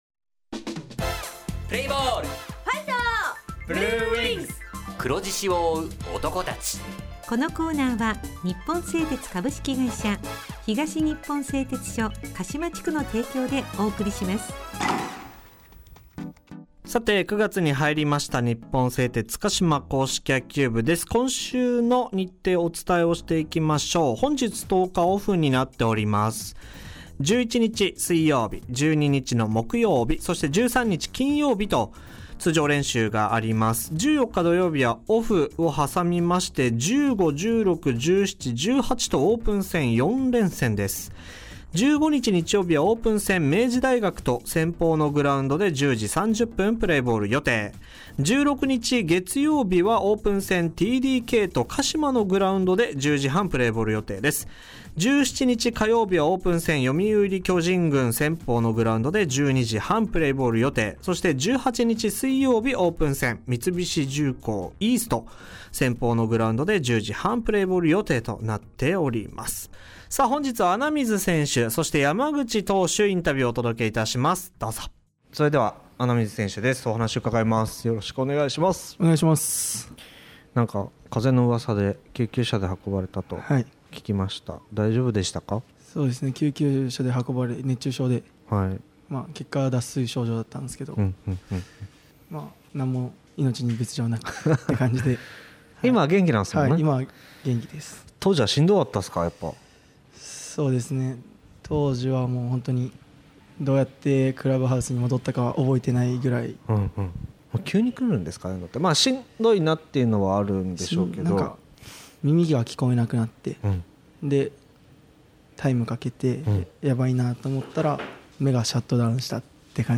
地元ＦＭ放送局「エフエムかしま」にて鹿島硬式野球部の番組放送しています。
選手インタビュー